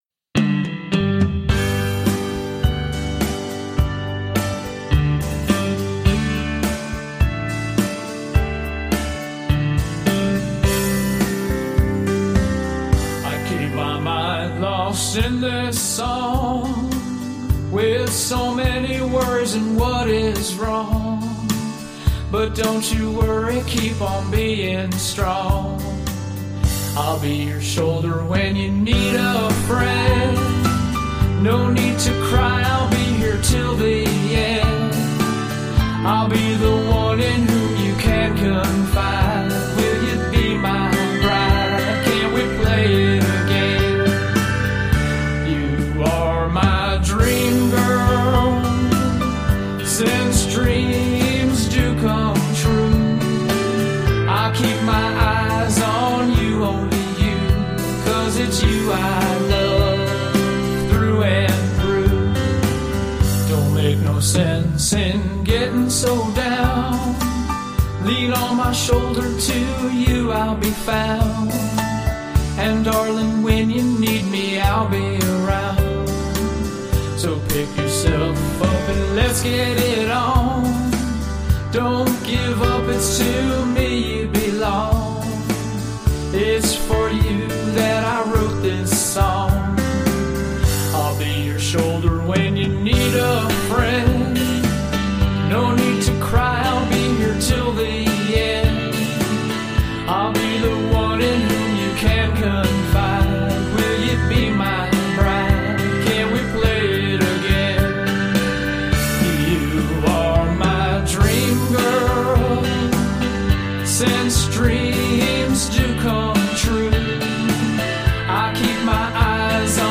• 5 hours in the studio
• One vocal take (10 min.)
• Shorter original version